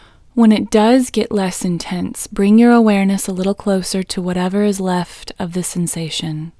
IN Technique First Way – Female English 13